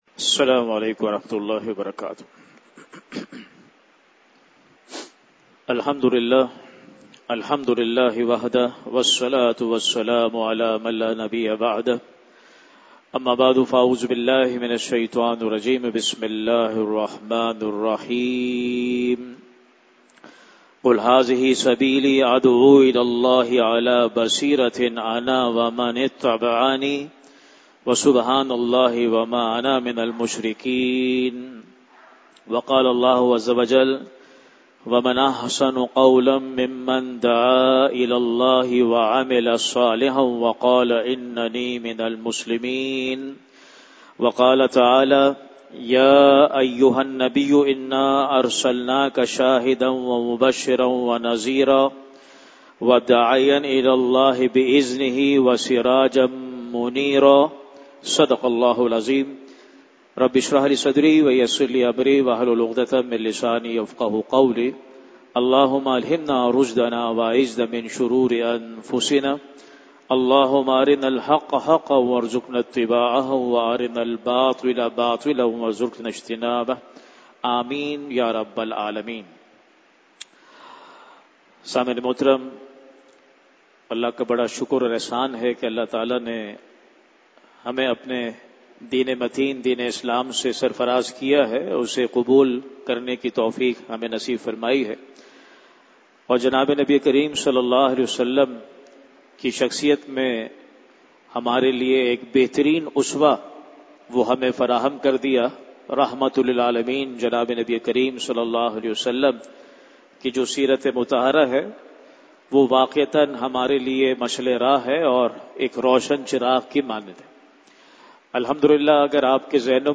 Khutbat-e-Jummah (Friday Sermons)
@ Masjid Jame-ul-Quran, Gulshan-e-Maymar 2025-10-31 Nabi Karim ﷺ ki Mustaqil Sunnat...Dawat-e-Deen